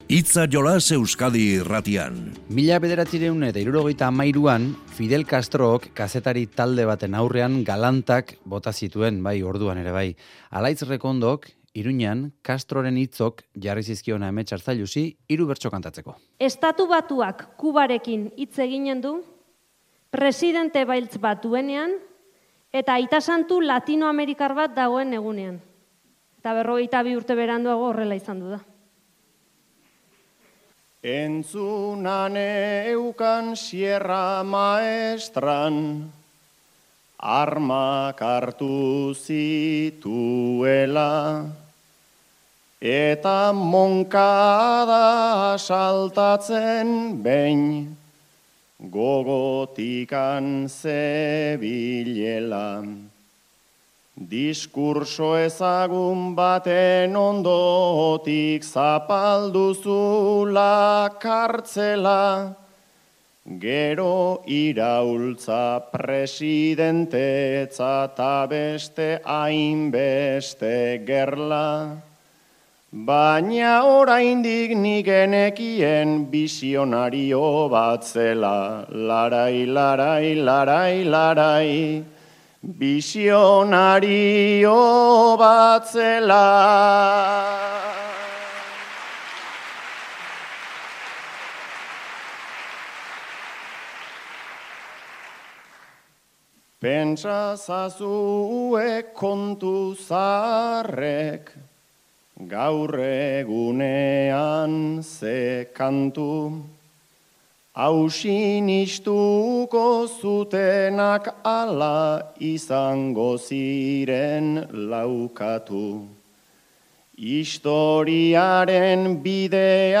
Iruñeako Bertsoaroan botako bertsoak